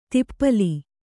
♪ tippali